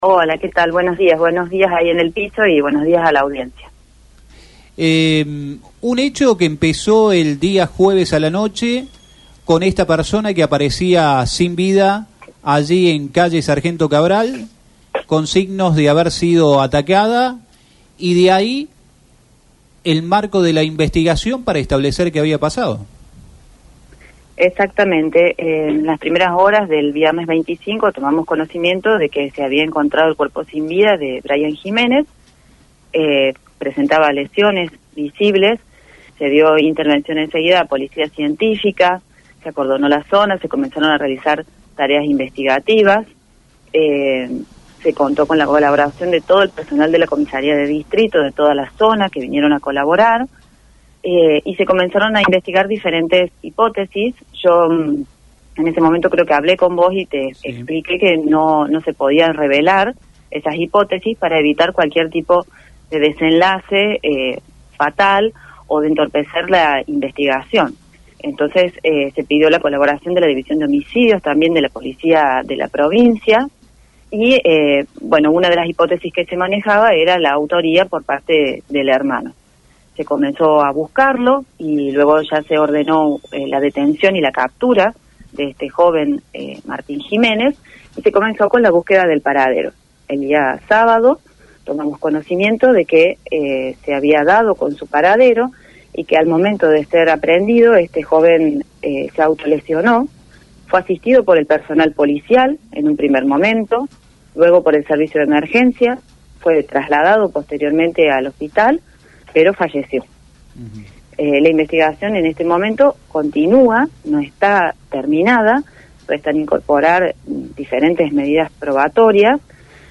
Audio de entrevista